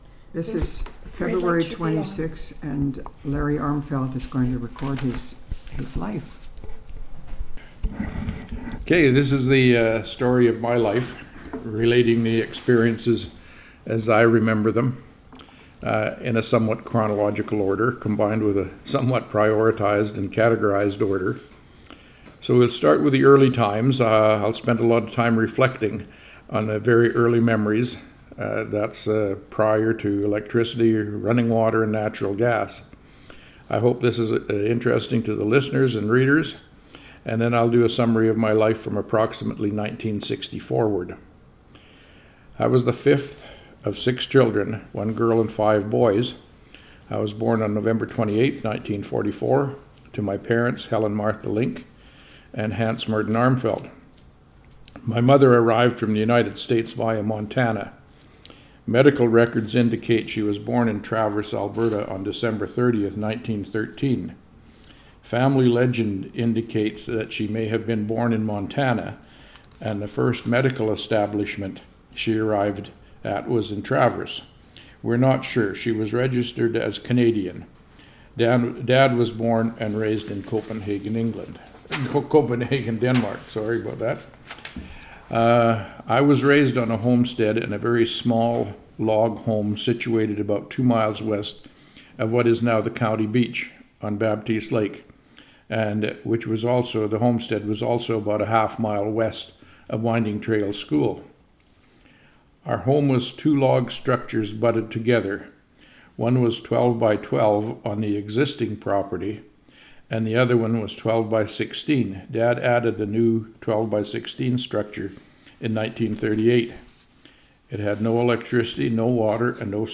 Audio interview, transcript of audio interview, booklet and newspaper article,